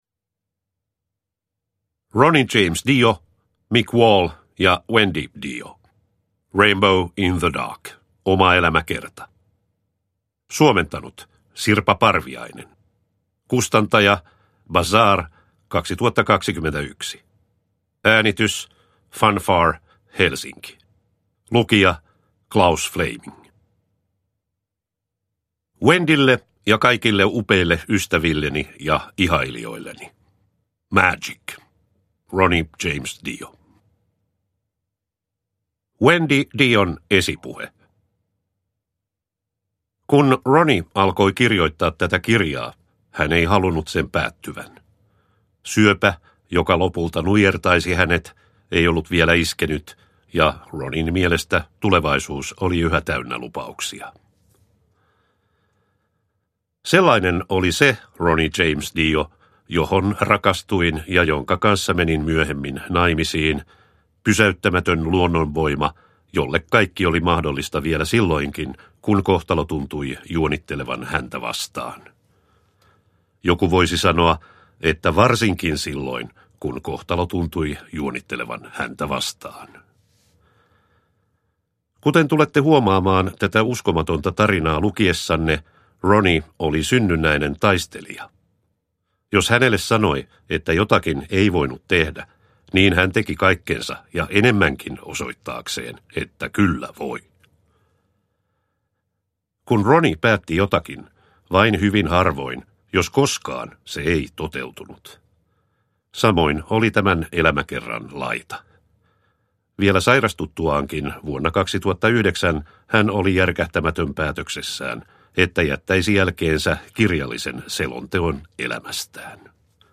Rainbow in the Dark: omaelämäkerta – Ljudbok – Laddas ner